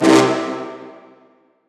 DDW4 ORCHESTRA 1.wav